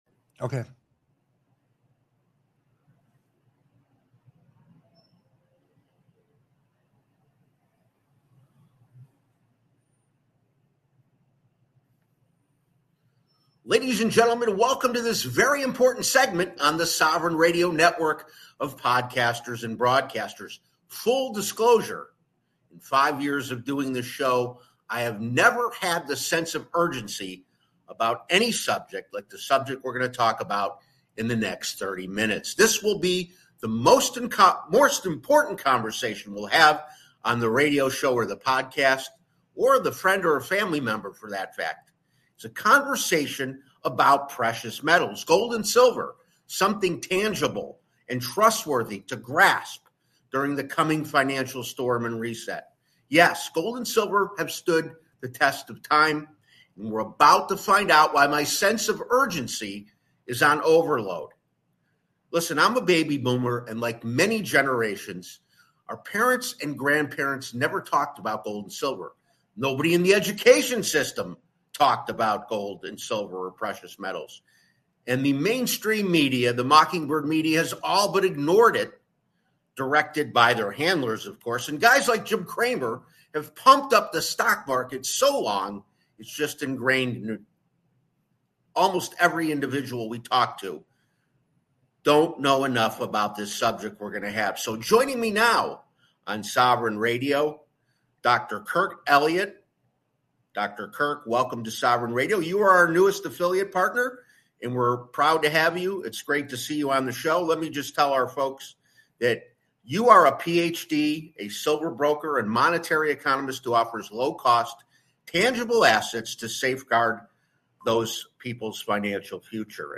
In this radio show, the host discusses the importance of investing in precious metals like gold and silver, especially during uncertain financial times.